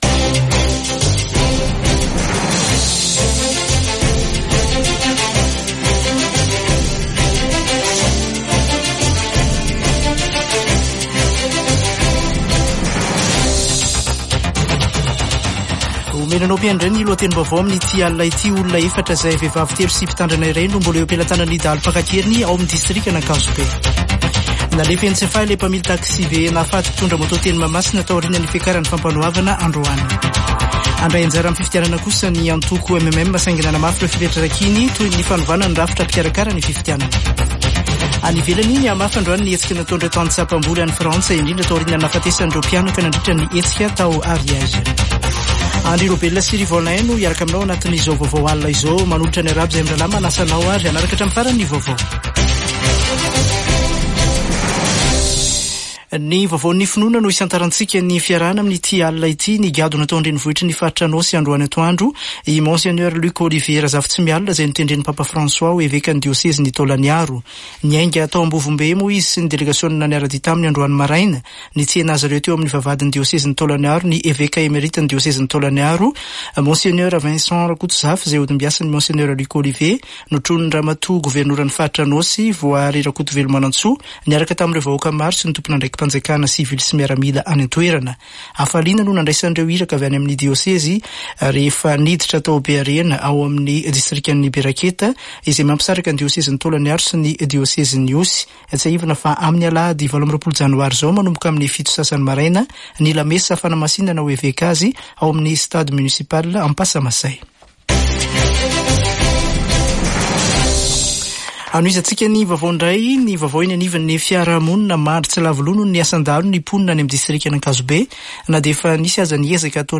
[Vaovao hariva] Alarobia 24 janoary 2024